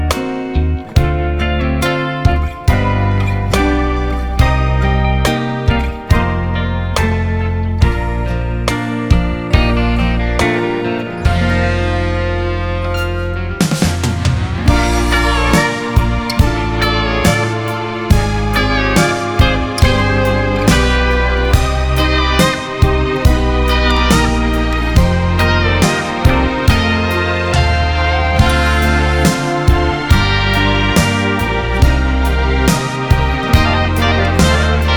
Жанр: Музыка из фильмов / Саундтреки
# Soundtrack